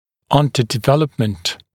[ˌʌndədɪ’veləpmənt][ˌандэди’вэлэпмэнт]недостаточное развитие, недоразвитие
underdevelopment.mp3